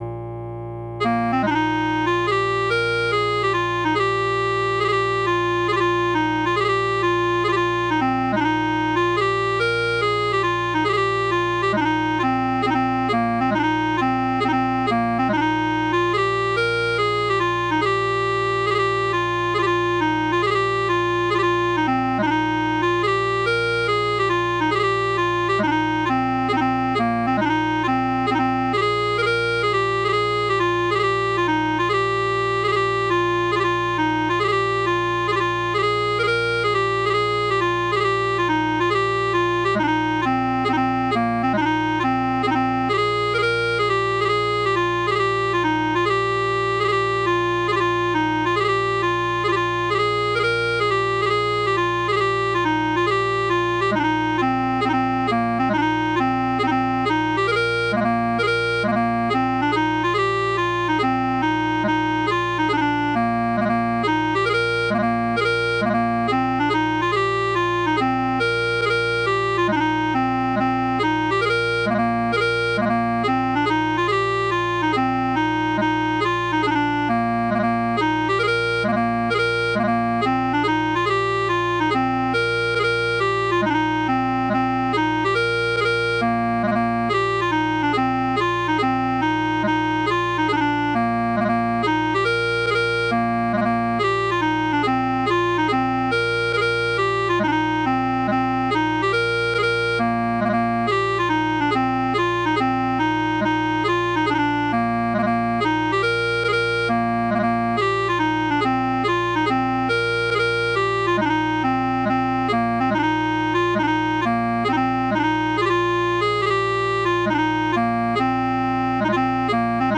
Bagpipes and Drums
PIPES: BWW/ PDF/